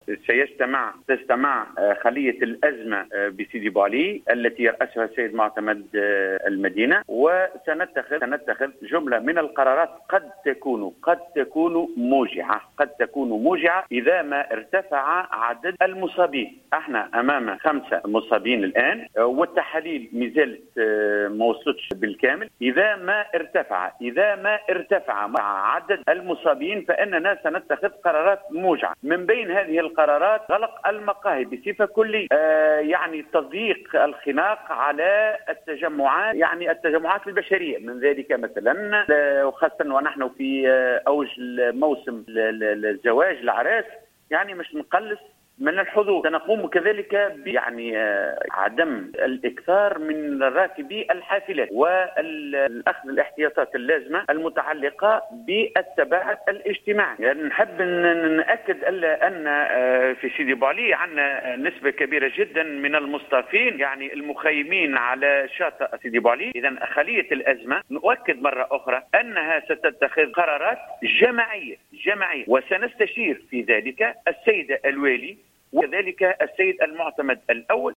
وأكد رئيس المجلس البلدي بسيدي بوعلي إبراهيم بوبكر، في تصريح للجوهرة أف أم، أن الخلية قد تتخذ قرارات وصفها بالموجعة إذا ما ارتفع عدد المصابين والذين يقدر عددهم حاليا بخمسة أشخاص.